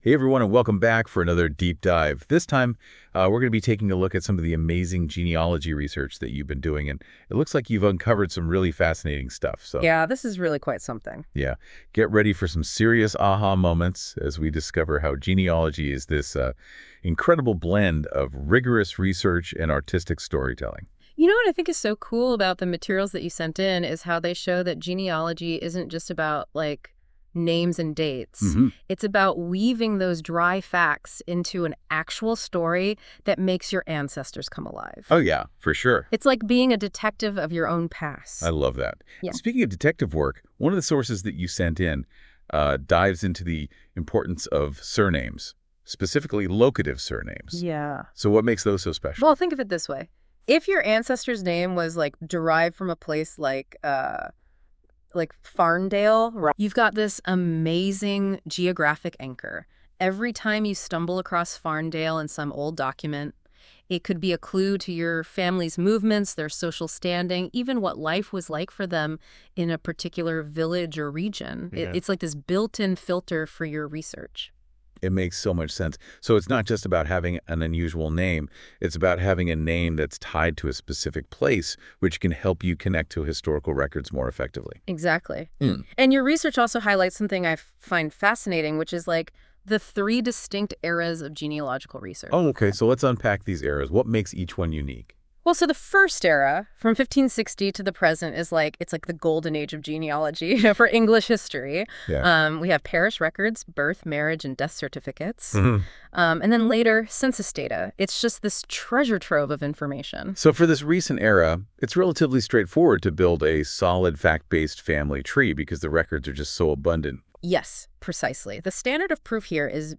Using Google�s Notebook LM, listen to an AI powered podcast summarising this page.